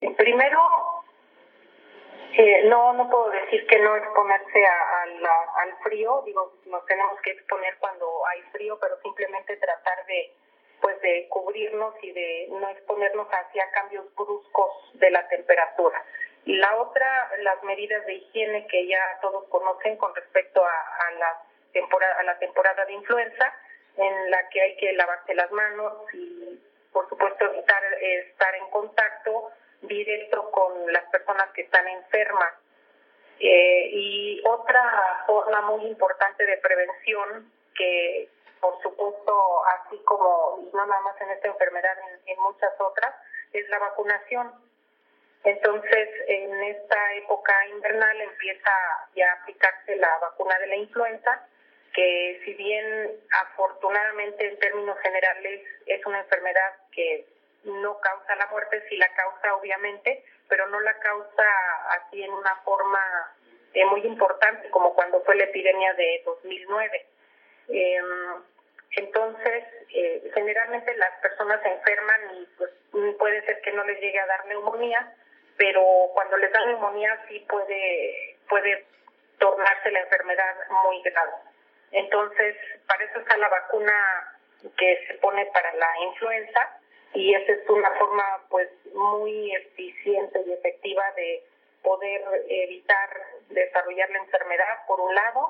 En entrevista, la especialista señaló que en en México la neumonía se ubica entre las primeras 20 causas de muerte y explicó que la neumonía es un proceso infeccioso de los pulmones provocado por bacterias, virus u hongos.[audio